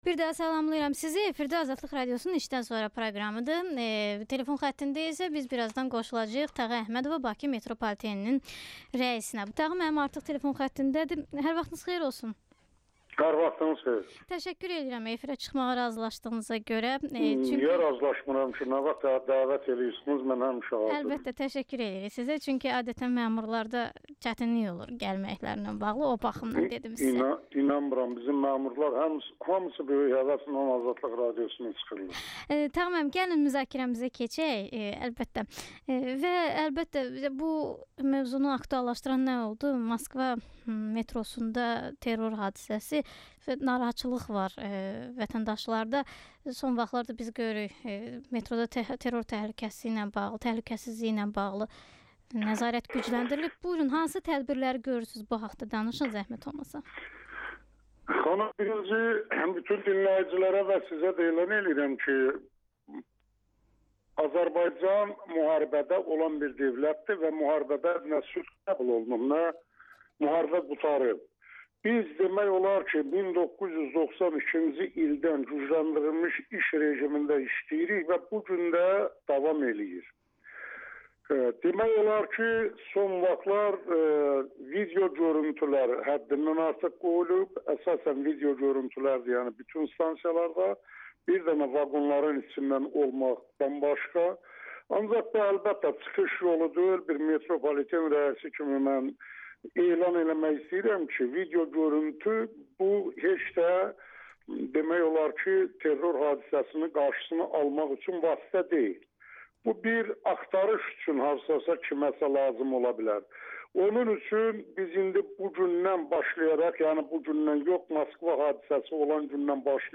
Tağı Əhmədovla müsahibə
Bakı Metropoliteninin rəisi AzadliqRadiosunun "İşdən sonra" proqramında qonaq olub.